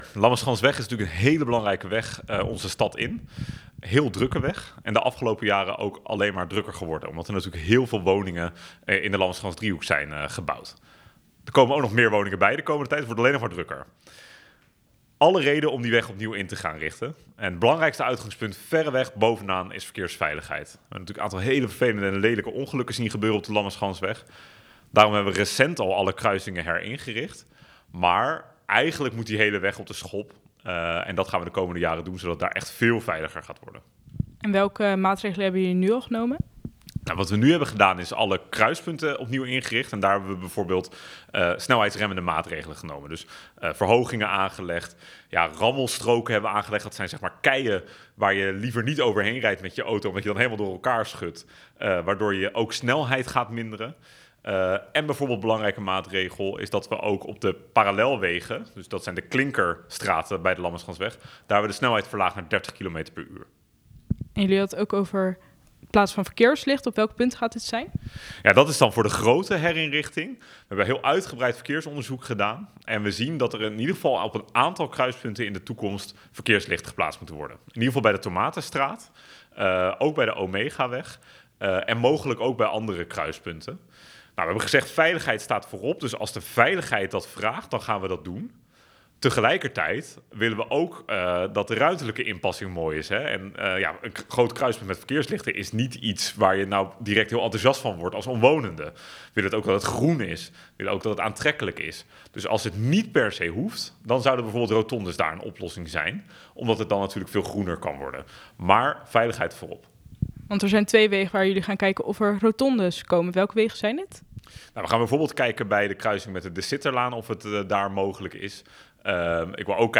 Verslaggever
wethouder Ashley North